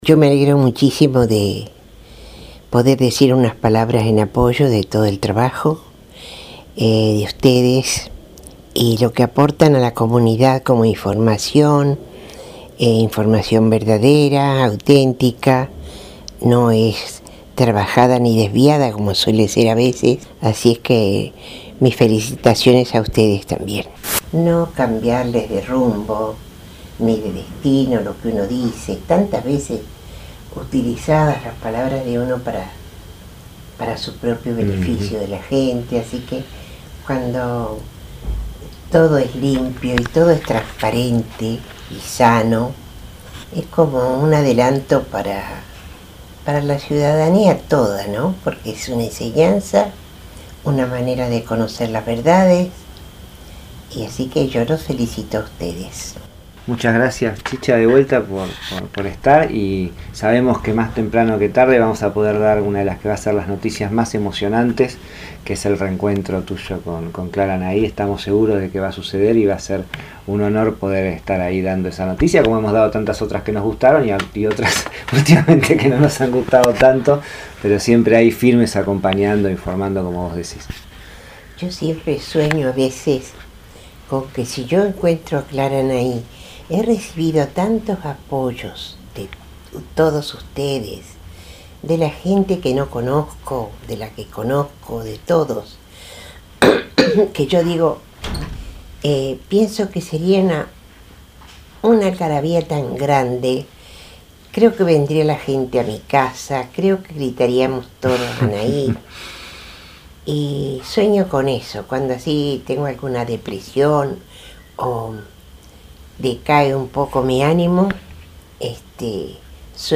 Hace menos de un mes, en el marco del festejo por el 10mo aniversario de Radio Estación Sur, Chicha -una de nuestras homenajedas- nos envío un saludo en el que además relató como se imaginaba el encuentro con su nieta Clara Anahí (el cual hoy vivimos de manera exacta): «Yo siempre sueño con que si encuentro a Clara Anahí… he recibido tantos apoyos de todos ustedes, de la gente que conozco y que no conozco, pienso que sería una algarabía tan grande, creo que vendría la gente a mi casa, que gritaríamos todos Anahí! Cuando tengo alguna depresión o decae un poco mi ánimo, siempre sueño con ese encuentro, de esa manera».